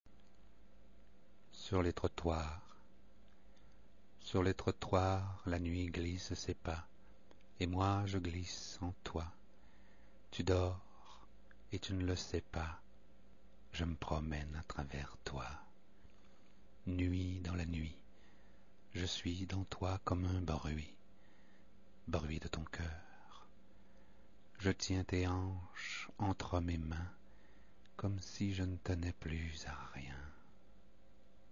lit les po�mes de Bruno Bernier